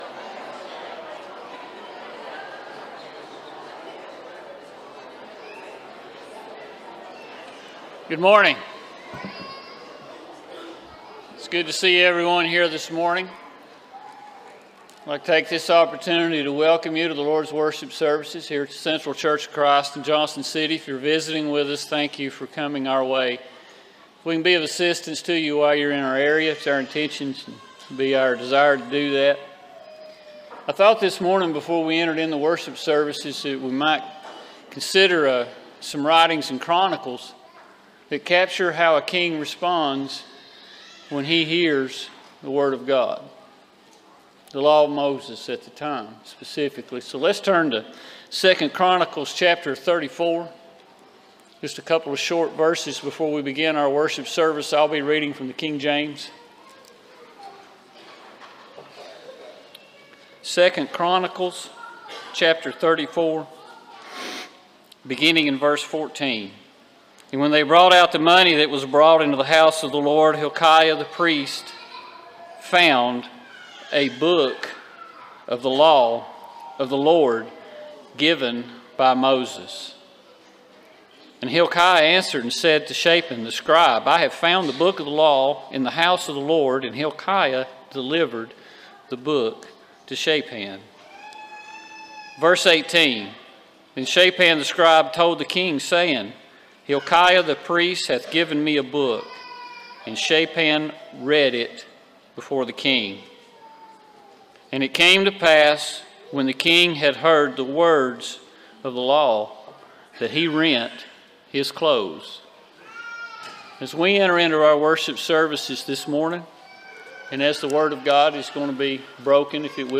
Series: Sunday AM Service